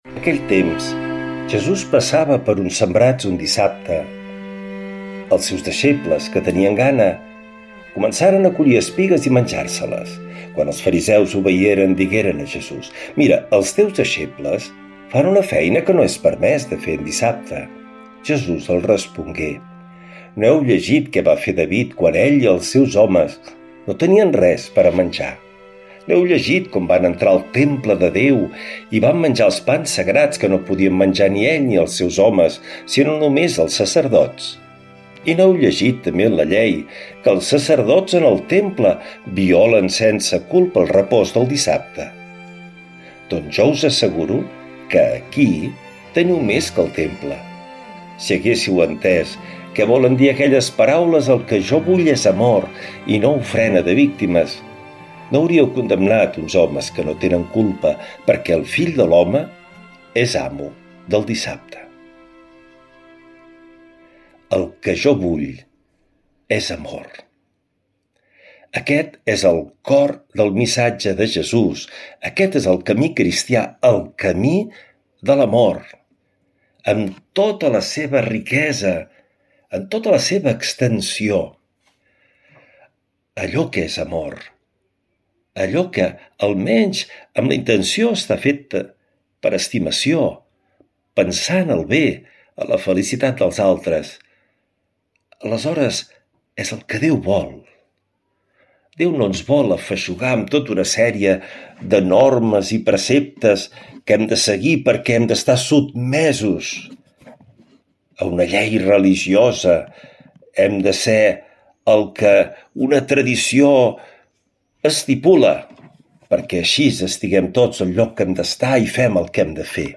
L’Evangeli i el comentari de divendres 18 de juliol del 2025.
Lectura de l’evangeli segon sant Mateu